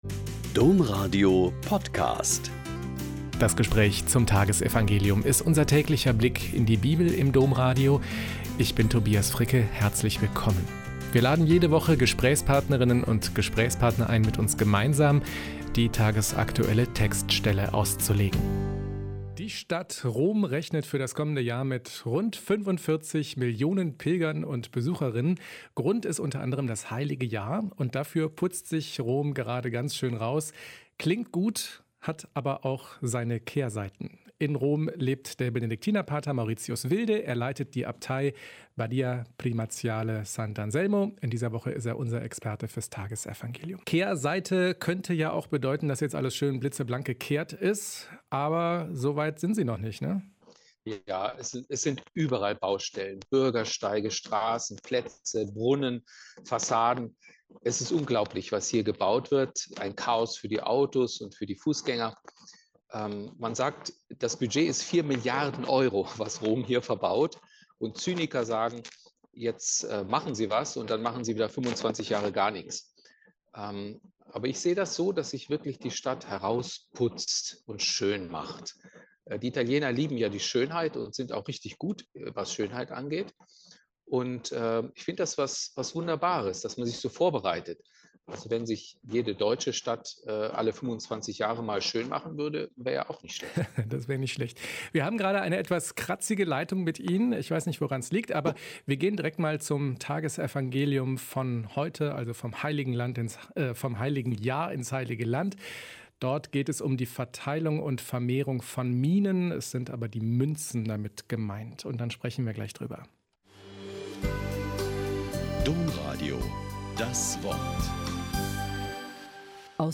Lk 19,11-28 - Gespräch